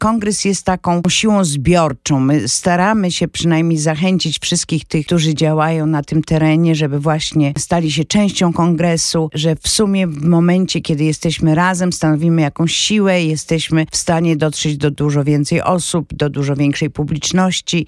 W studio Radio Deon Chicago gość